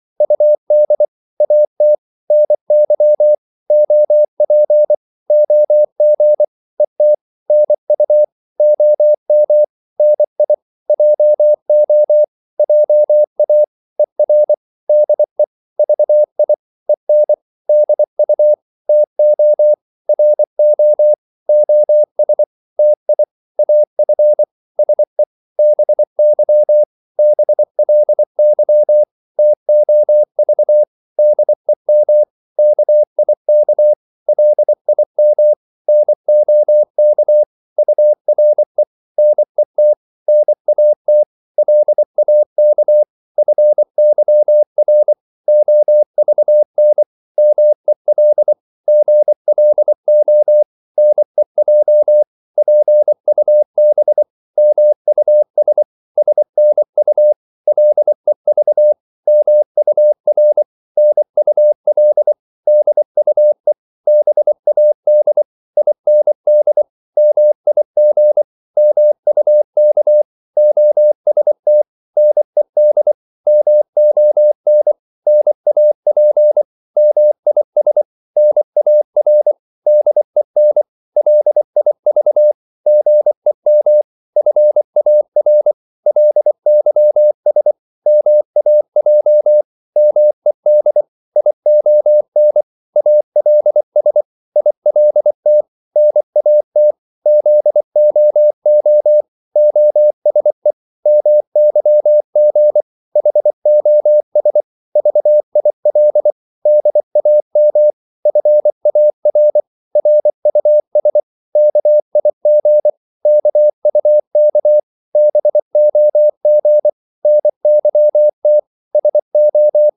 Korte danske ord 24wpm | CW med Gnister
Korte ord DK 24wpm.mp3